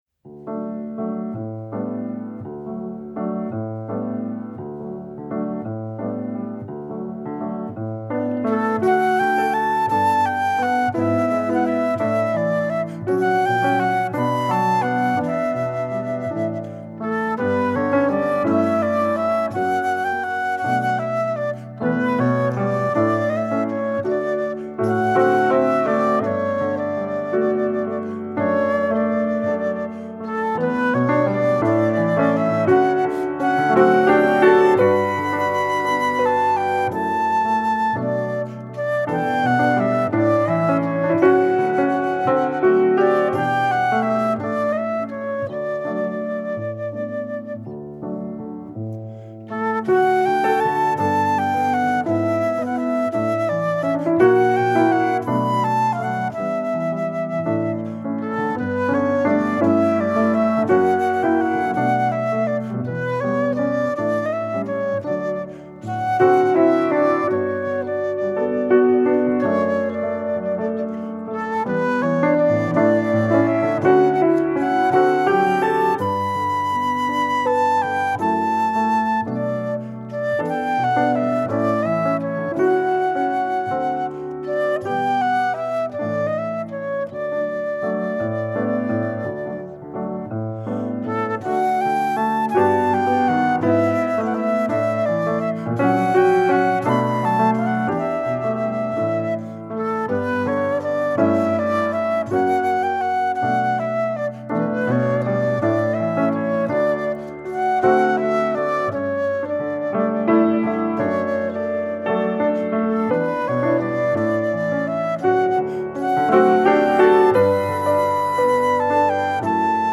Her i en instrumental version: